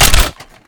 weap_hvy_gndrop_4.wav